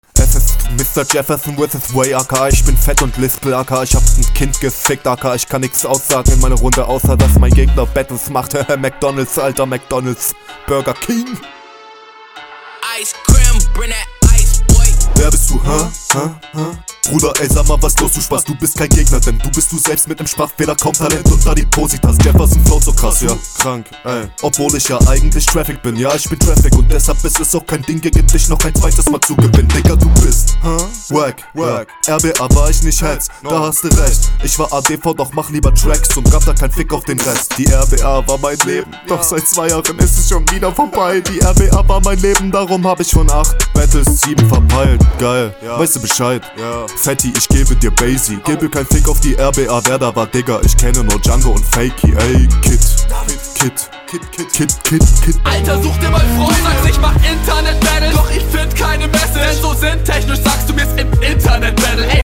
Intro okay parodiert, auch wenn ich generell kein Fan von Intros bin.
Sehr witzig gekontert wie immer eigentlich, intro und outro super geil haha.